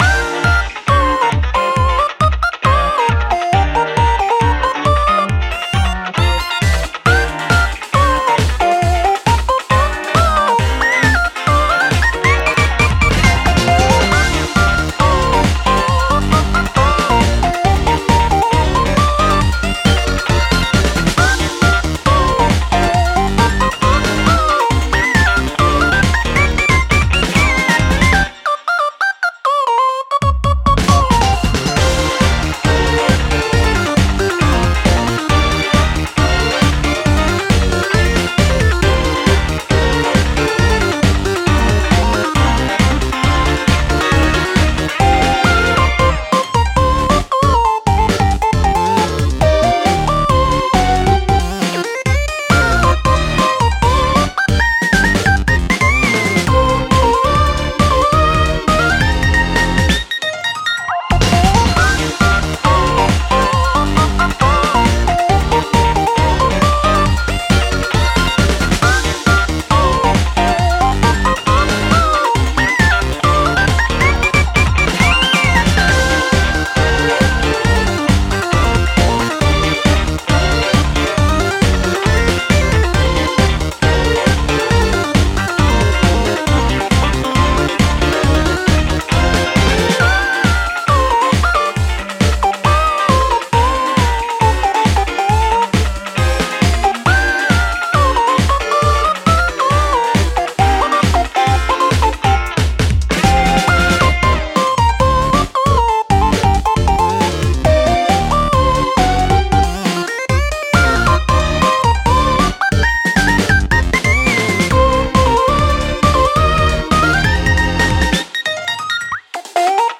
ループ用音源（BPM=136）